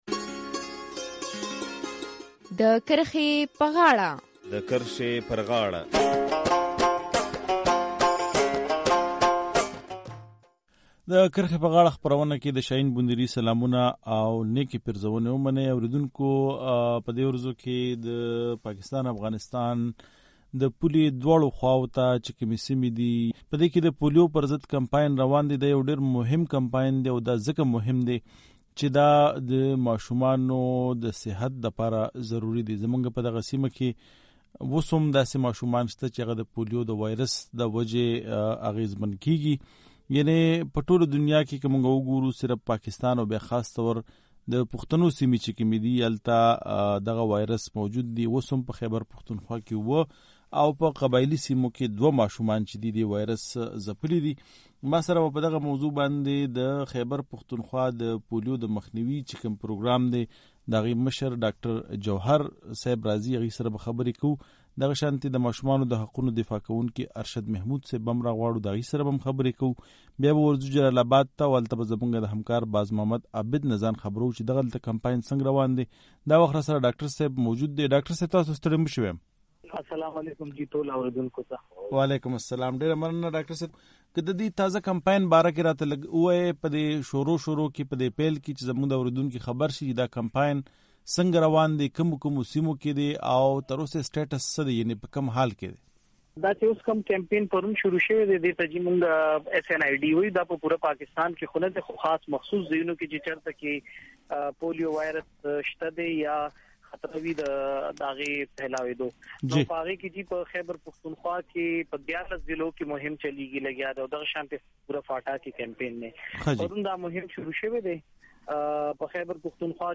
په خېبر پښتونخوا کې د روغتیا د څانګې چارواکي وايي د روان کال تر پای به د پولیو د واېرس نوغي وباسي. د کرښې په غاړه خپرونه کې دا ځل په دې موضوع بحث شوی